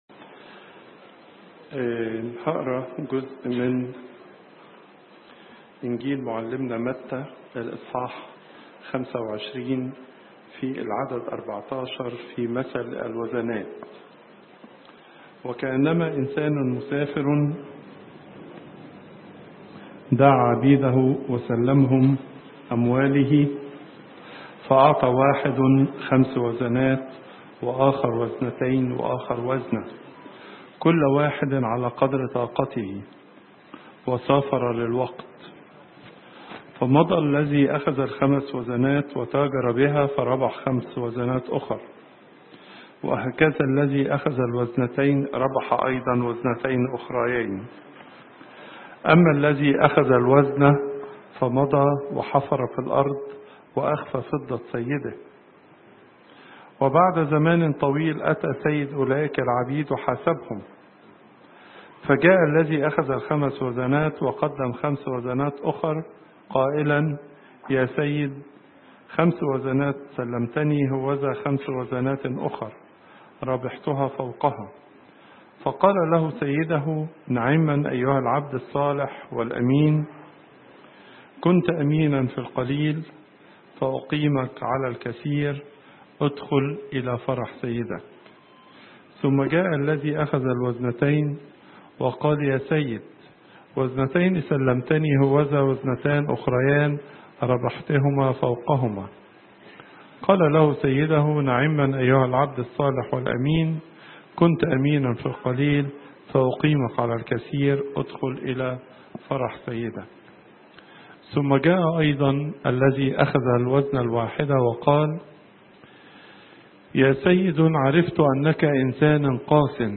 Pope Tawdroes II Weekly Lecture